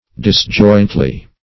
disjointly - definition of disjointly - synonyms, pronunciation, spelling from Free Dictionary Search Result for " disjointly" : The Collaborative International Dictionary of English v.0.48: Disjointly \Dis*joint"ly\, adv.